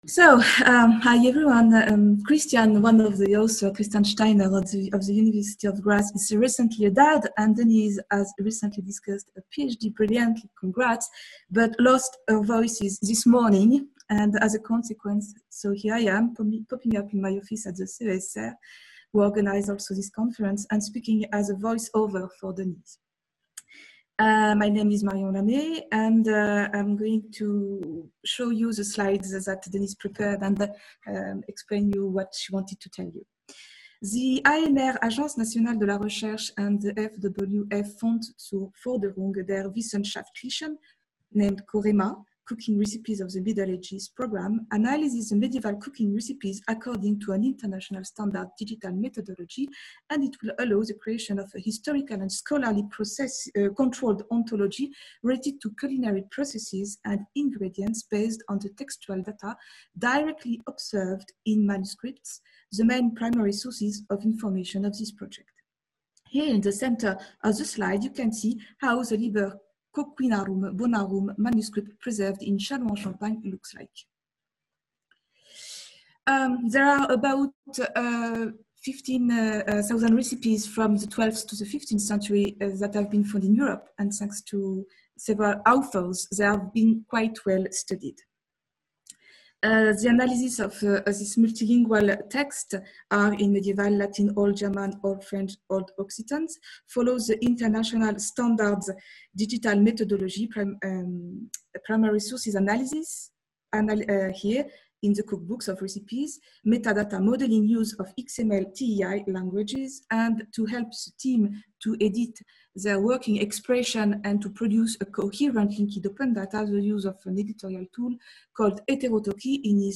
FR Flash talk